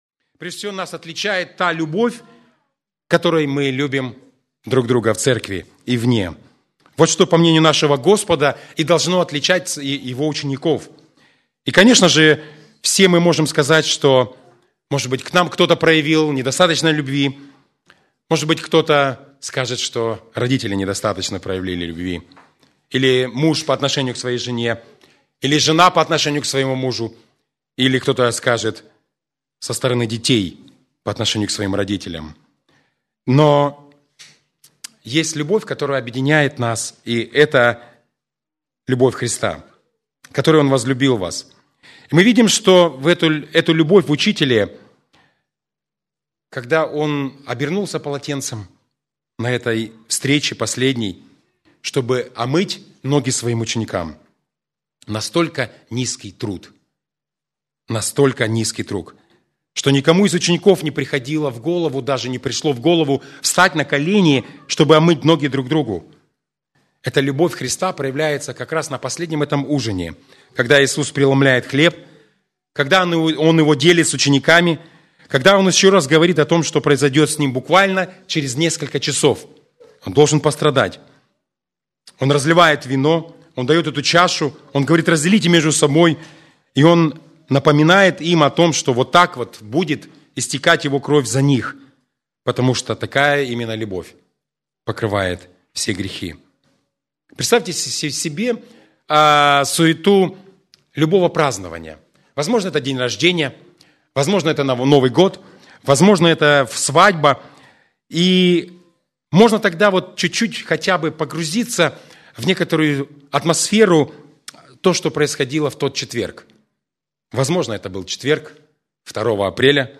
Церковь: Церковь "Преображение во Христе", г. Москва (Местная религиозная организация «Церковь евангельских христиан-баптистов «Преображение во Христе»)